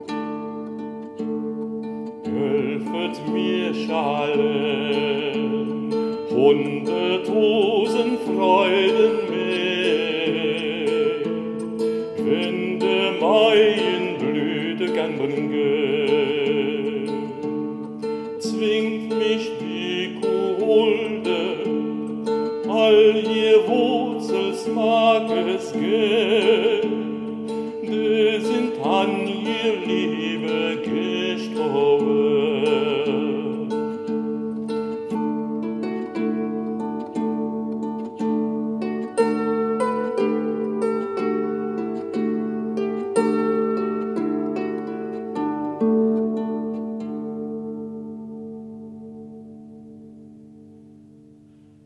Baryton-Basse
diffuse et interprète la musique médiévale.
Spécialiste de la lyrique courtoise des XIIème et XIIIème siècles, PANDORE est devenu une référence vocale et instrumentale pour l’interprétation de ces musiques très anciennes.